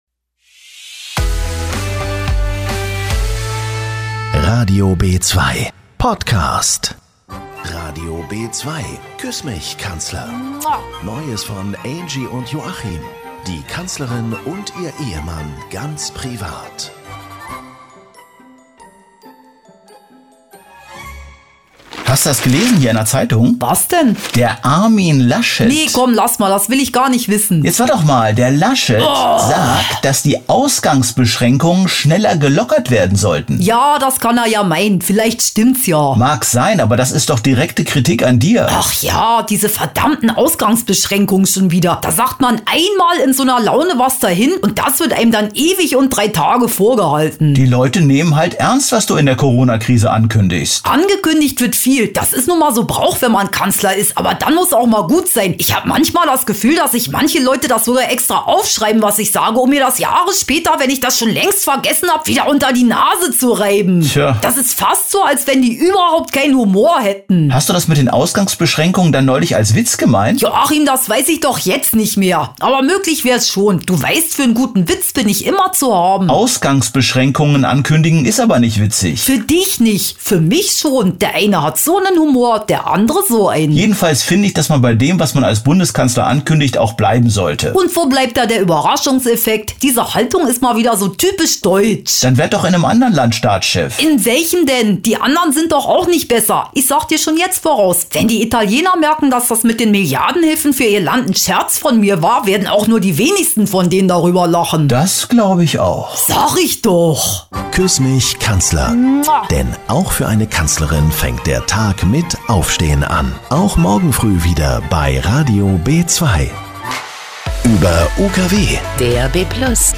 Comedy
Die beliebte radio B2-Comedy. Am Frühstückstisch mit Angela und Joachim.